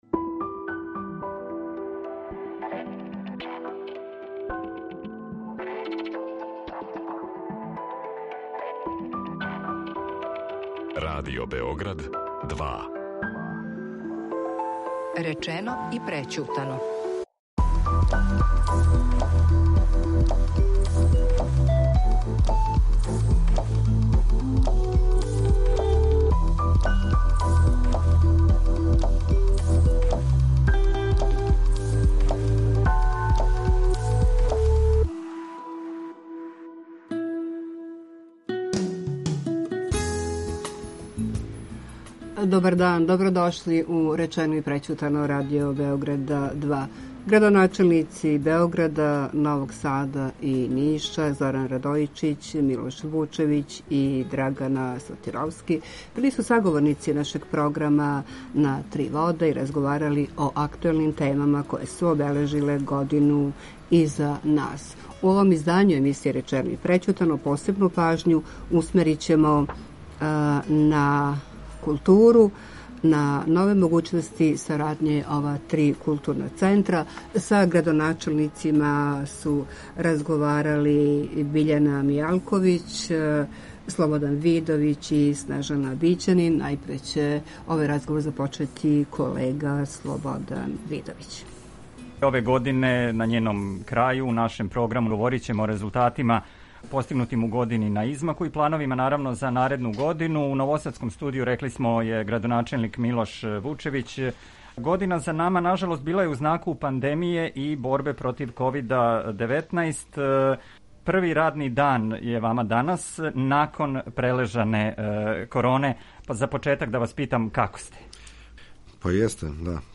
Градоначелници Београда, Новог Сада, Ниша ‒ Зоран Радојичић, Милош Вучевић и Драгана Сотировски разговараће о актуелним темама које су обележиле годину која је иза нас и плановима за годину која долази.
Три градоначелника из три студија